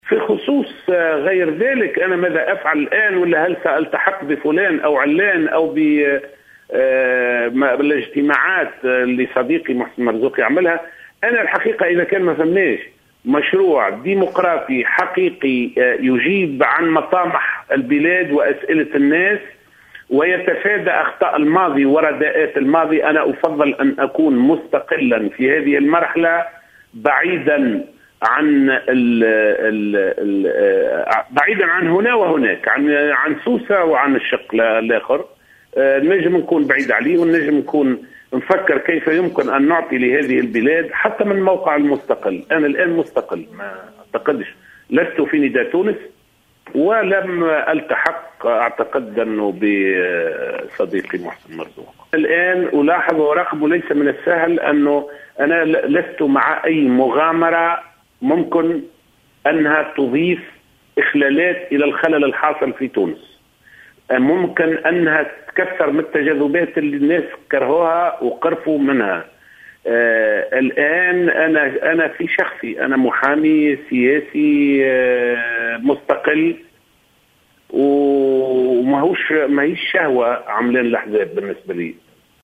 نفى القيادي السابق في حزب نداء تونس، لزهر العكرمي في تصريح ل"الجوهرة أف أم" اليوم، الاثنين التحاقه بالحزب الجديد لمحسن مرزوق.